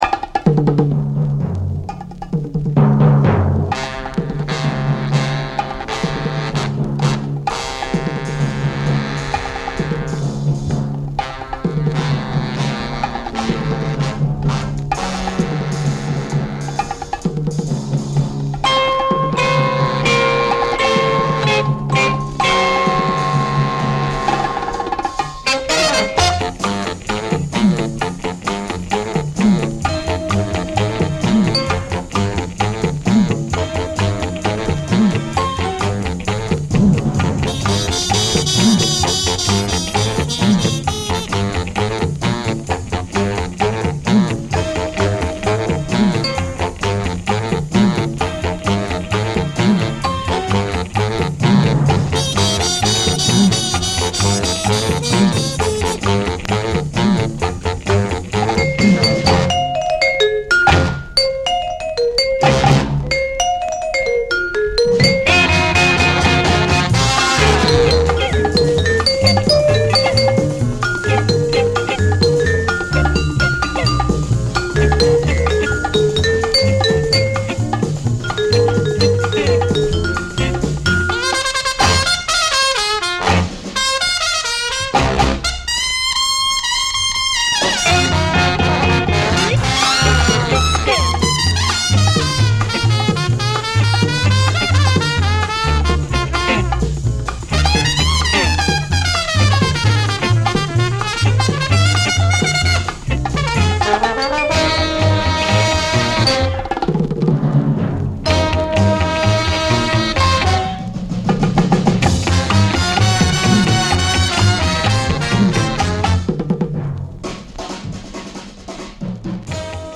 manipulating stereophonic effect